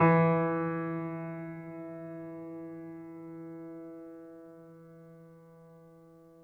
piano-sounds-dev
Steinway_Grand
e2.mp3